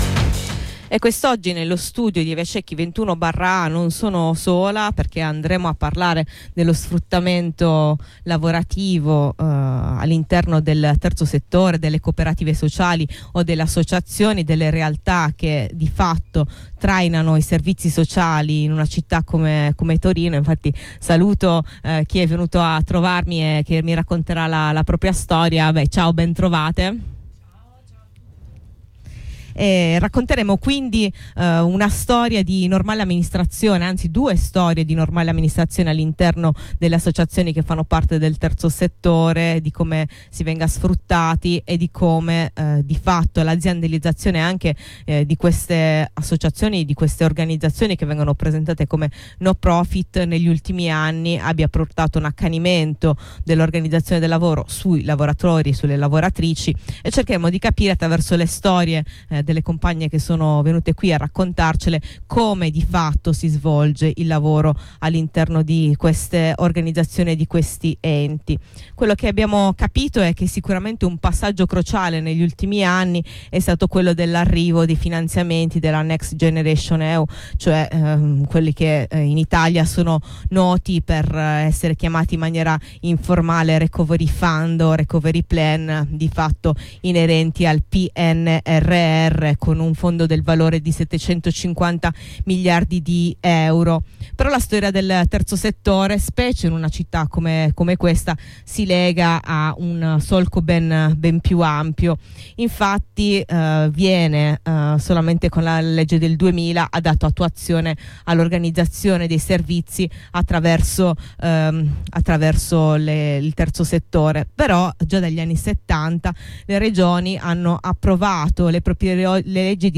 La cruda realtà quotidiana di associazioni e cooperative sociali, ammantate di regola di retoriche sulla parità e i diritti, viene svelata ai microfoni di Blackout dalle parole di alcune ex lavoratrici delle associazioni Almaterra ed Eufemia sulle vicende di sfruttamento ed estromissione che le hanno coinvolte negli ultimi mesi.